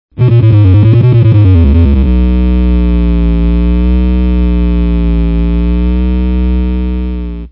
oct2 only